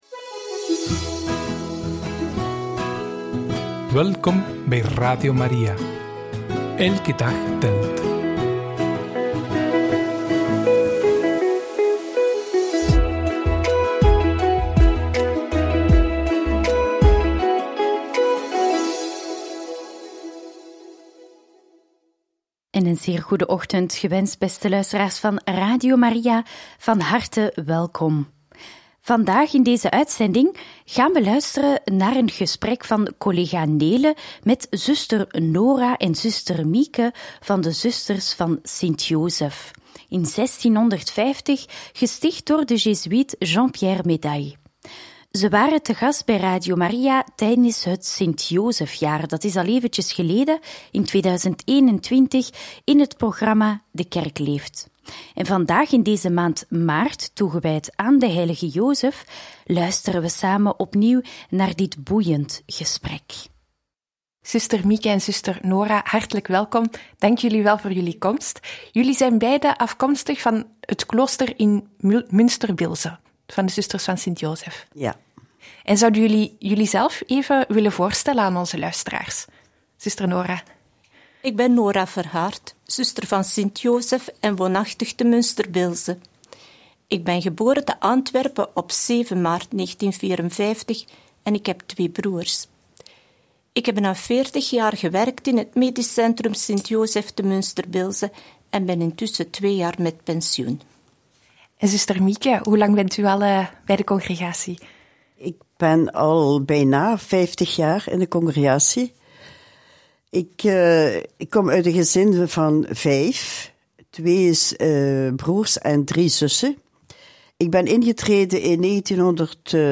Over de zusters van Sint Jozef: in gesprek